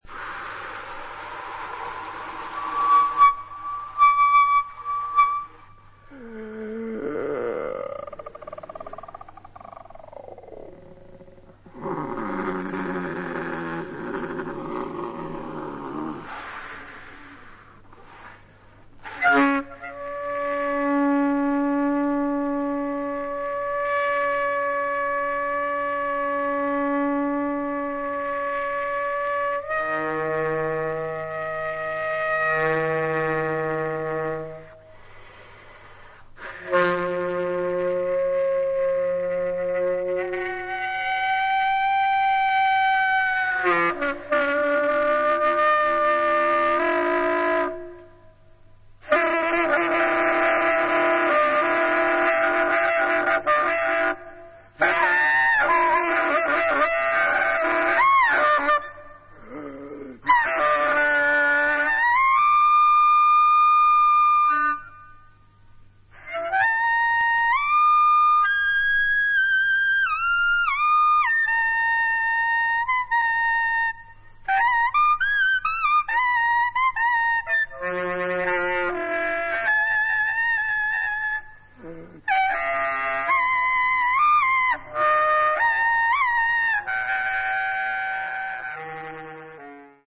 土着的な息吹が感じられる